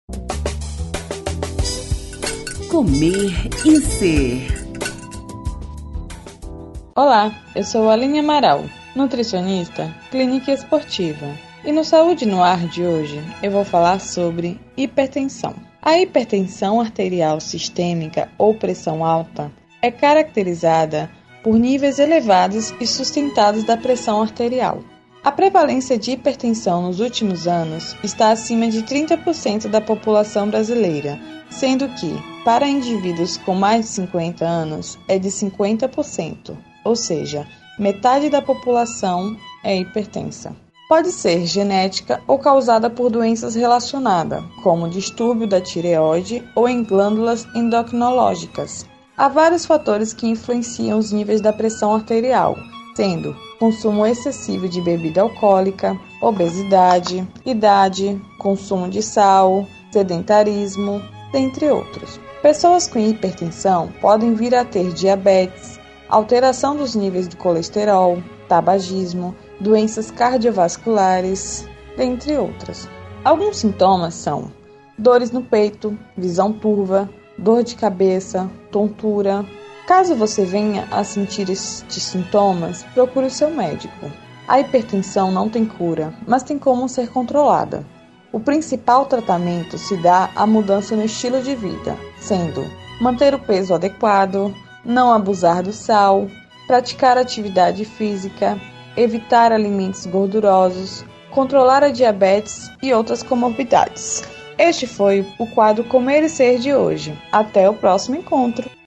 que vai ao ar no Programa Saúde No Ar veiculado pela Rede Excelsior de Comunicação: AM 840, FM 106.01, Recôncavo AM 1460 e Rádio Saúde no ar / Web.